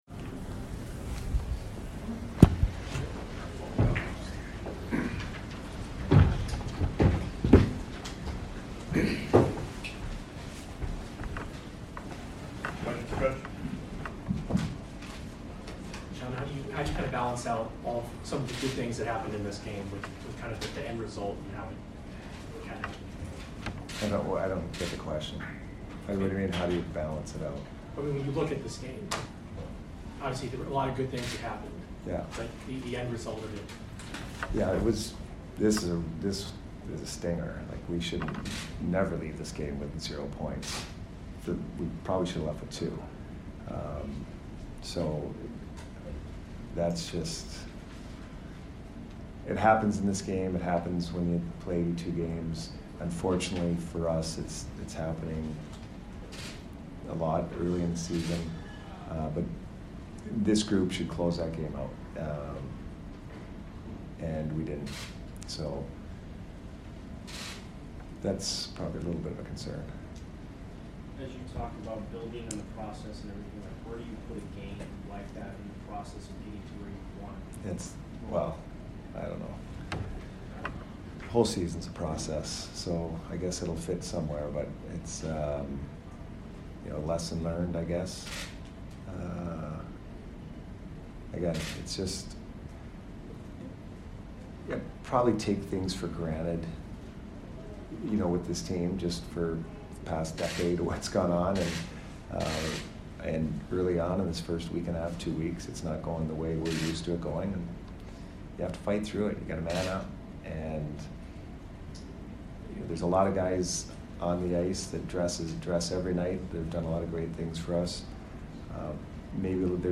Head Coach Jon Cooper Post Game 10/18/22 vs. PHI